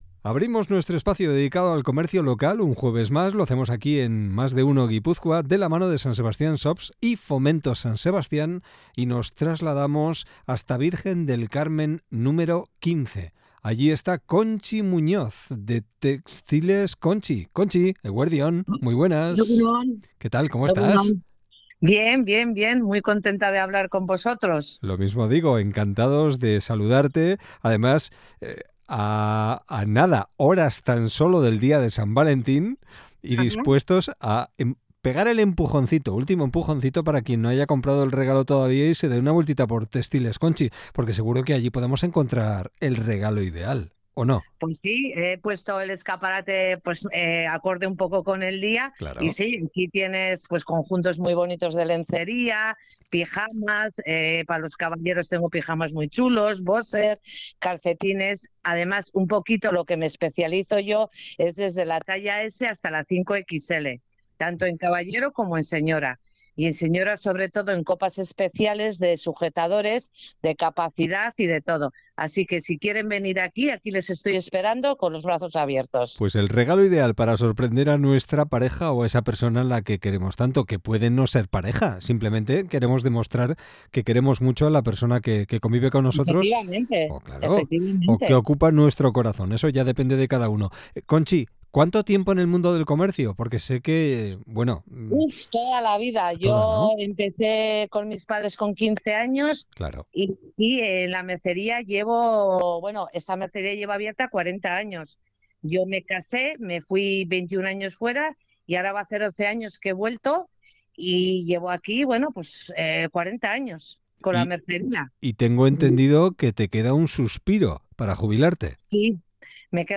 No te pierdas la entrevista completa: